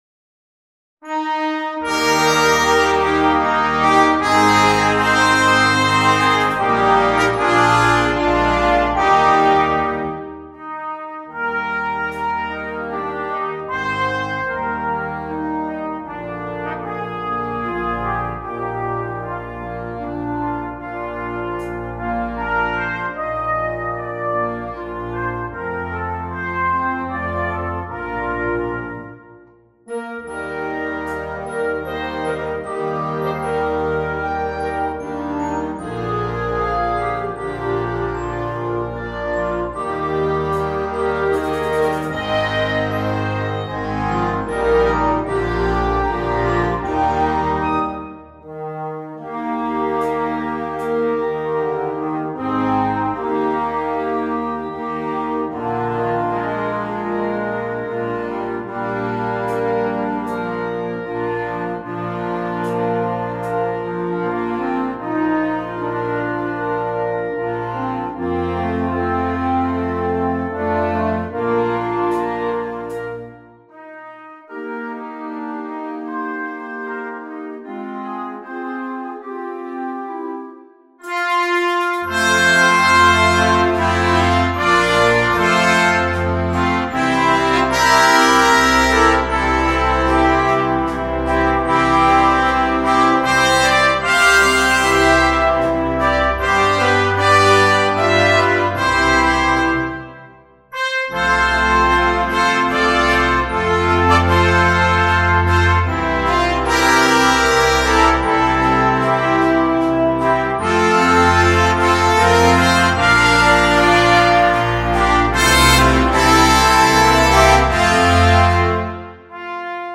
2. Concert Band
Traditional
Full Band
without solo instrument
Christmas Music